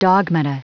Prononciation du mot dogmata en anglais (fichier audio)
Prononciation du mot : dogmata